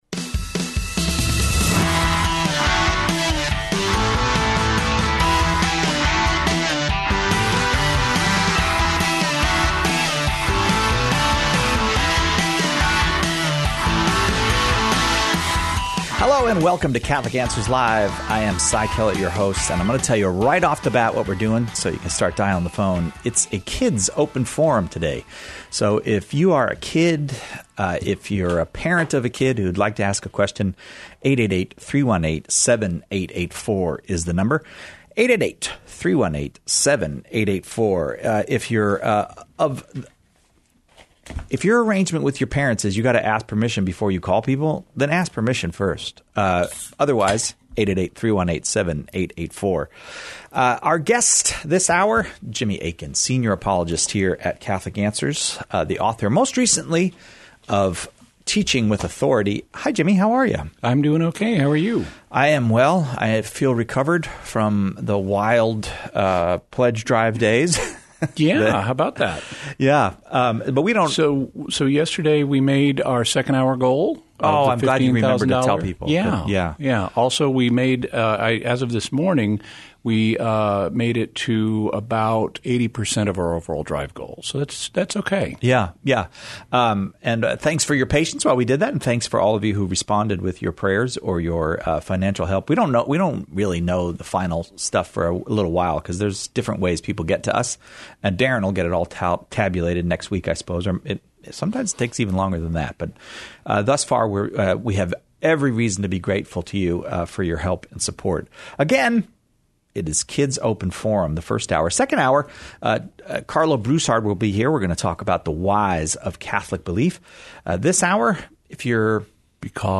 Children between the ages of 3 and 16 are welcome to call with their questions about the faith. Tune in for an hour of thoughtful and intelligent questions from the youngest members of the Church.